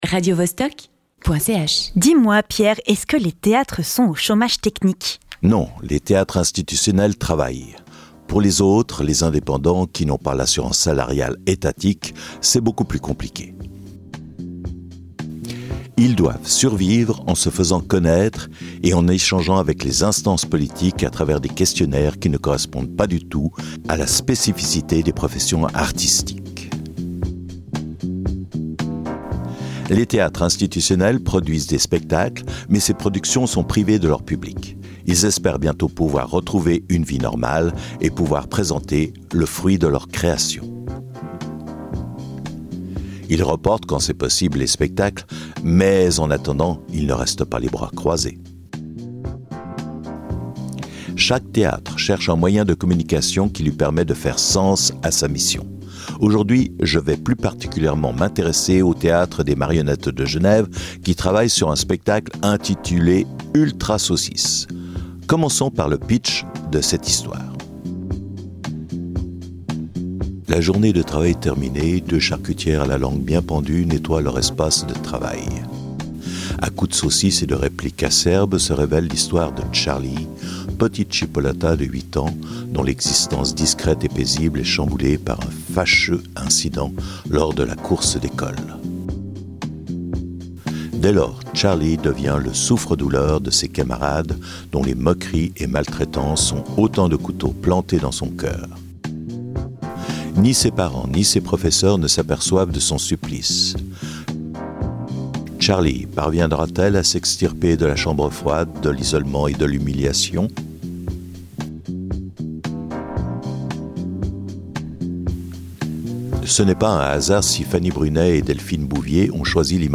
Rejoignez le TMG pour un reportage streaming inédit dans les coulisses de notre nouvelle création ULTRA SAUCISSE !